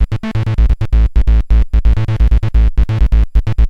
SONS ET LOOPS GRATUITS DE BASSES DANCE MUSIC 130bpm
Basse dance 1 G